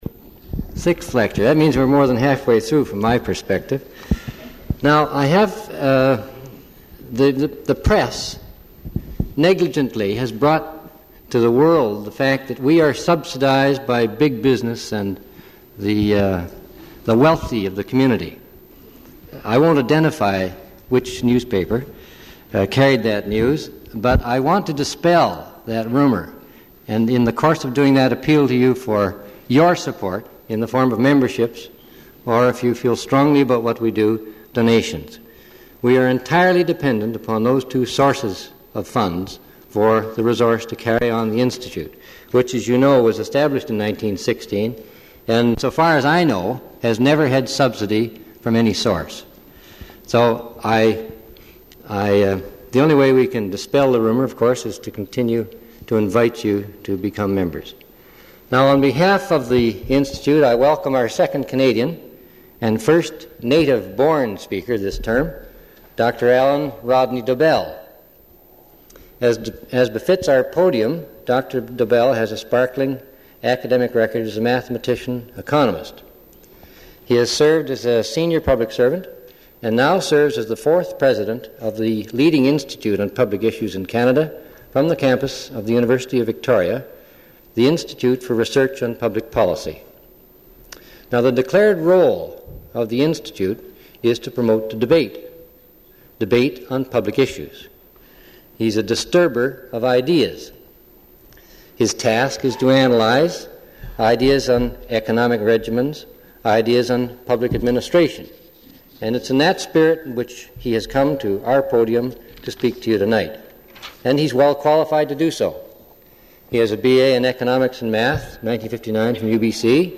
Original audio recording available in the University Archives (UBC AT 1129).